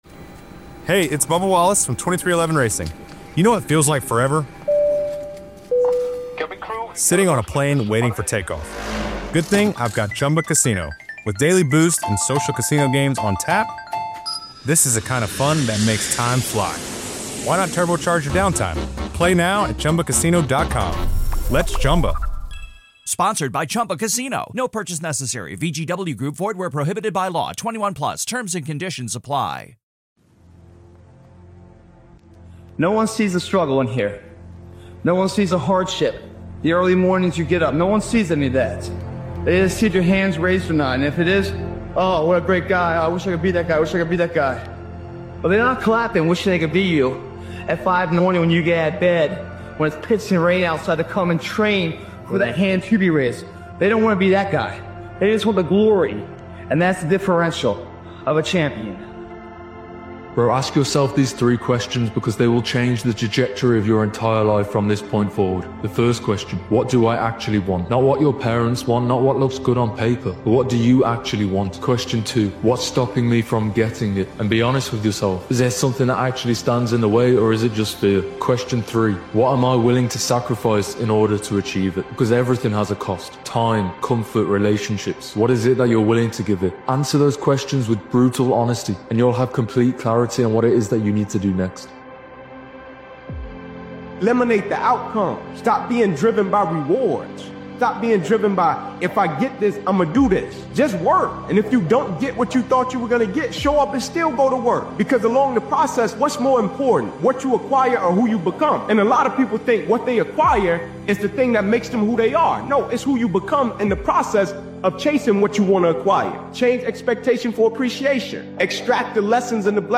Powerful Motivational Speech is a raw and defiant motivational episode created and edited by Daily Motivations. This powerful motivational speeches compilation speaks to the moment when exhaustion hits and quitting feels tempting—but stopping now would mean betraying everything you’ve worked for.